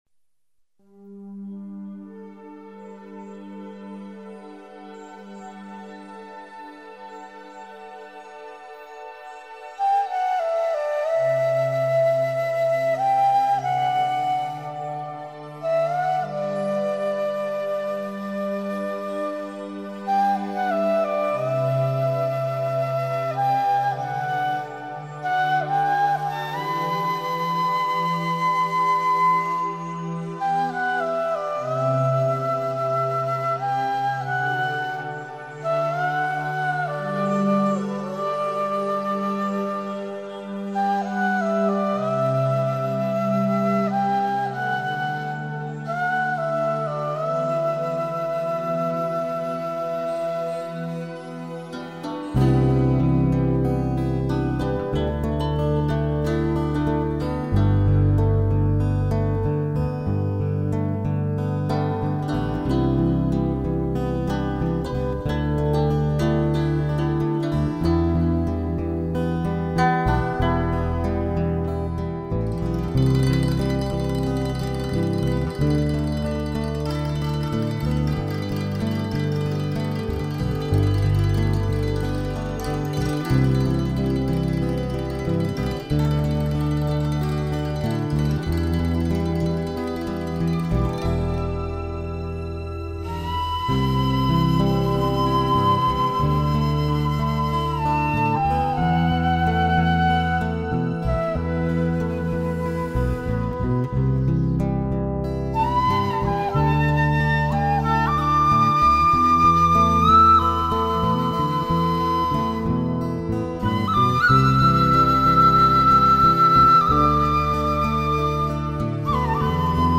以東方人的橫笛，輔以多元而和諧的配器，呈現的清新不僅能帶給你完全的放鬆